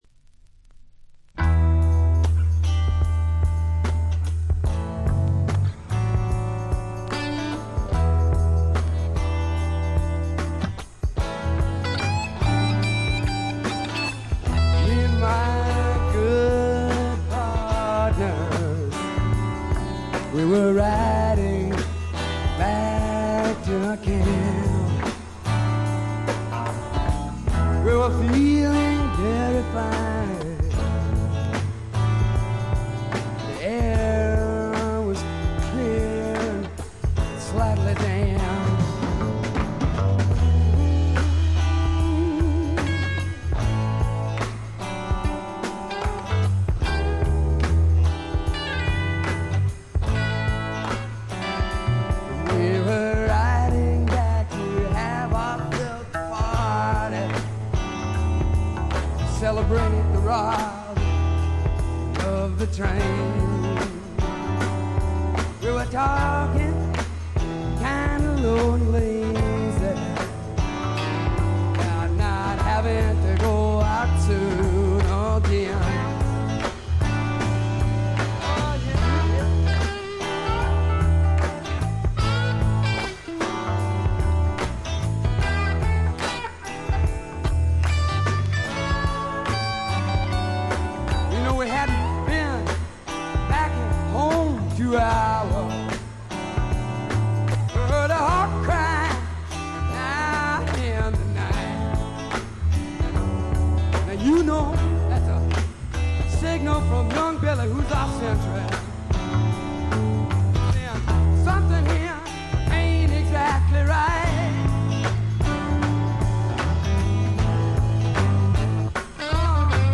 全体に細かなチリプチが出ていますが、特に目立つノイズはなくA-寄りの良品です。
試聴曲は現品からの取り込み音源です。